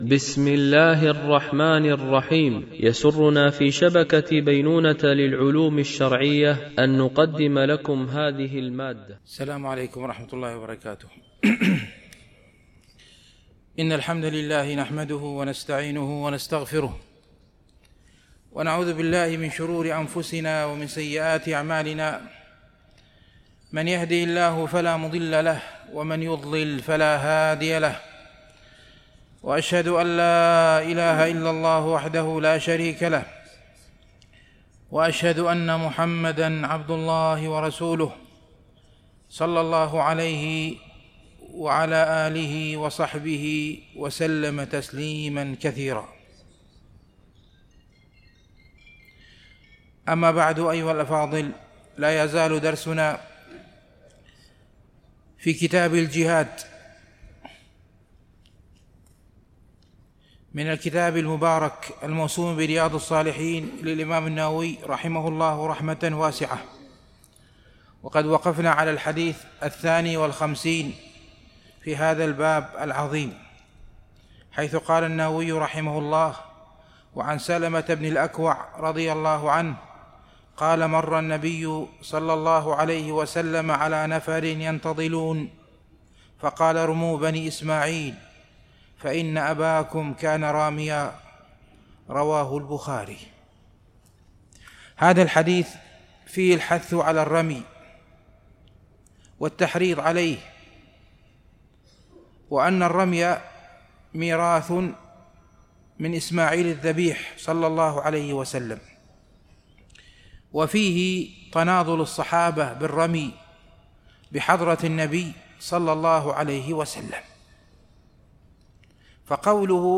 Mono